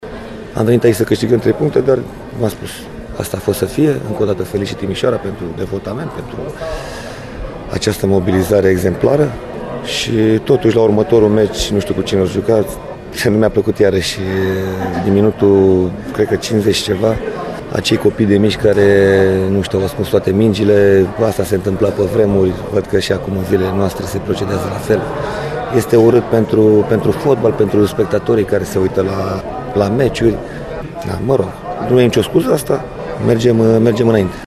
La declarațiile de după meci